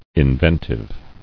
[in·ven·tive]